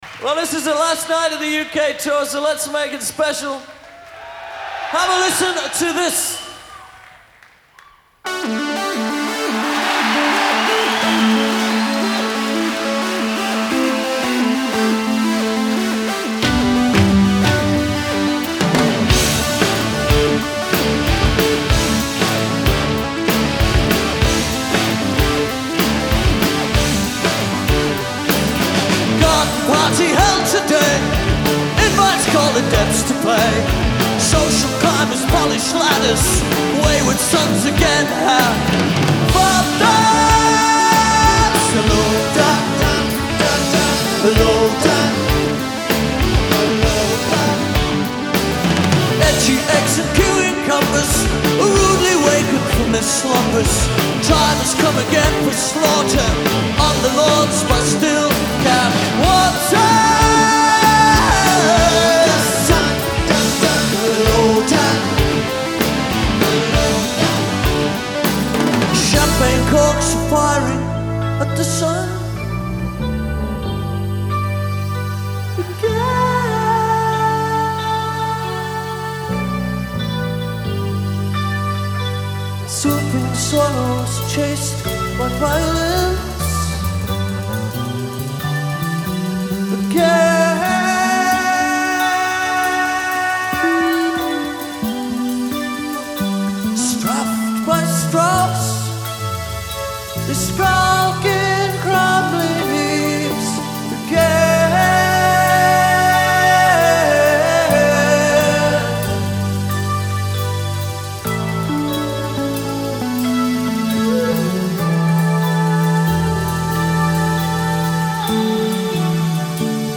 Genre : Progressive Rock